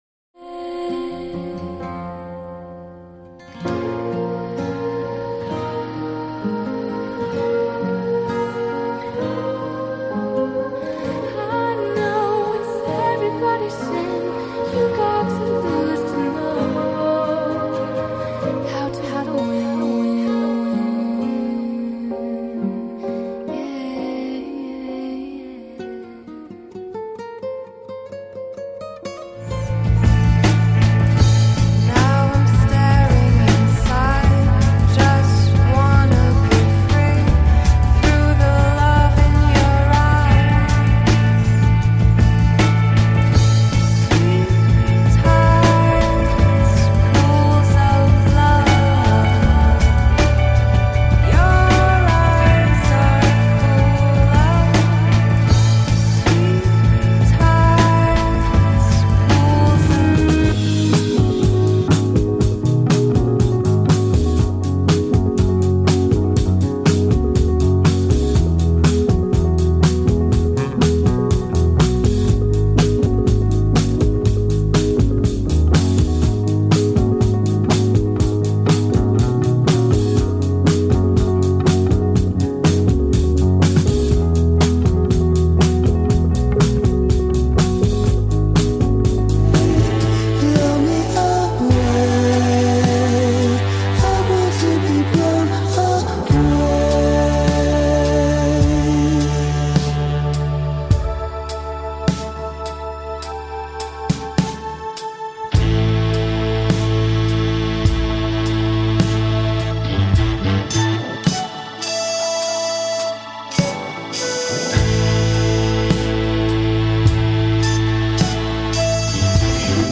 Calm & Eclectic
Eclectic Mix of Relaxing Songs